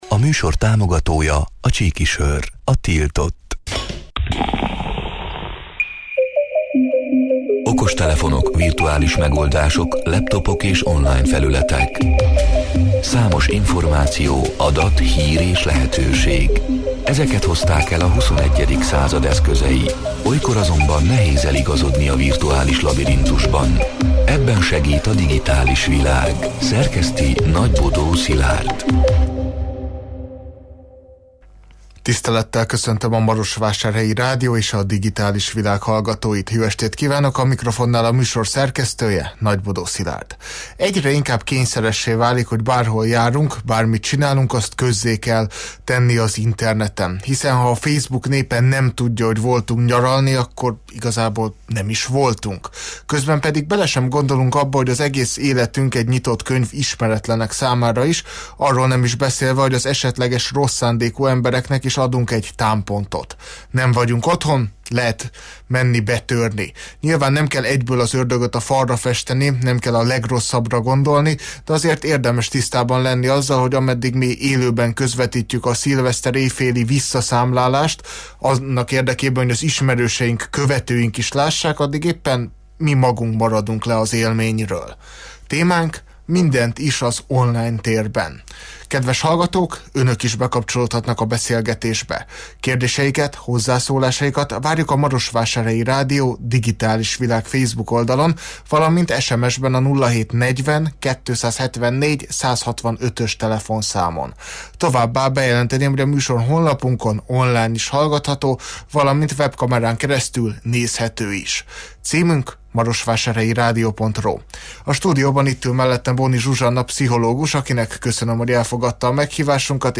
A Marosvásárhelyi Rádió Digitális Világ (elhangzott: 2025. január 7-én, kedden este nyolc órától élőben) c. műsorának hanganyaga: Egyre inkább kényszeressé válik, hogy bárhol járunk, bármit csinálunk azt közzé kell tenni az interneten.